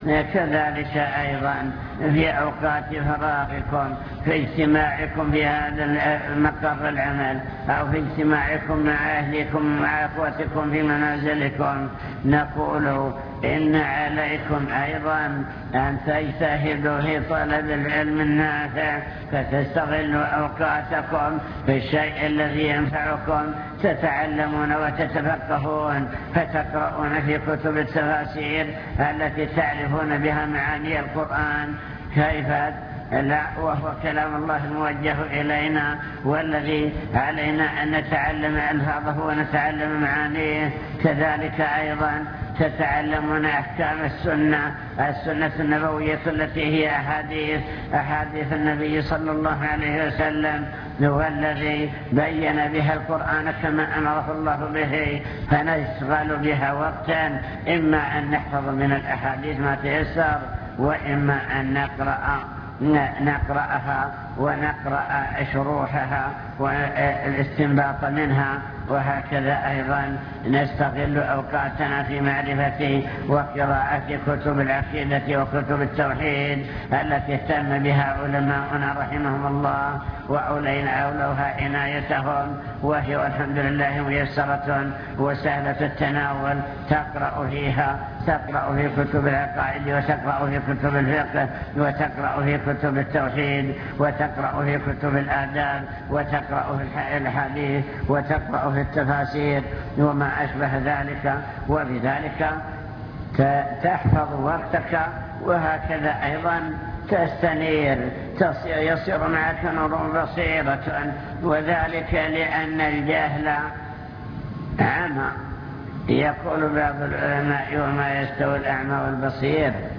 المكتبة الصوتية  تسجيلات - محاضرات ودروس  محاضرة بعنوان شكر النعم (2) واجب الإنسان نحو النعم